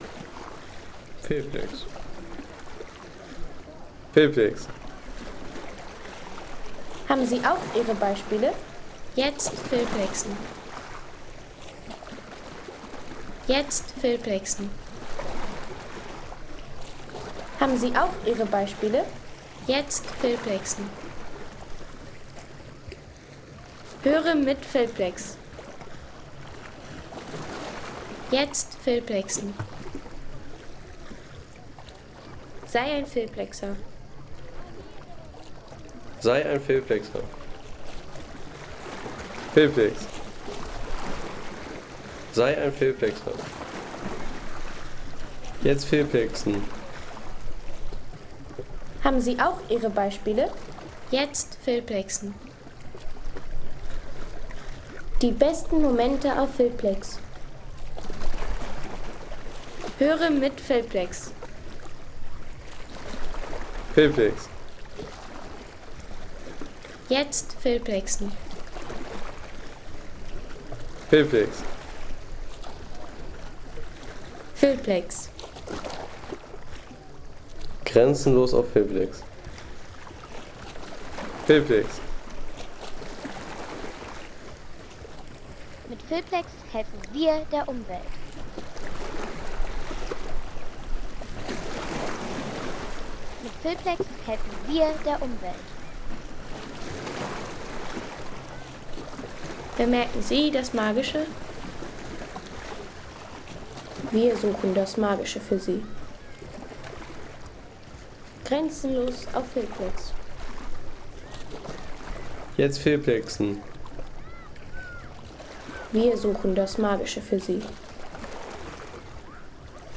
Žnjan City Beach in Split | Sommerliche Strandatmosphäre
Žnjan City Beach in Split | Entspannter Sommer-Strandsound
Authentische Sommeratmosphäre vom Žnjan City Beach in Split mit sanften Wellen und entspannter Küstenstimmung.
Eine warme Strandatmosphäre aus Split mit sanften Wellen, sommerlicher Lebendigkeit und echtem Küstengefühl für Filme, Reisevideos und Sound-Postkarten.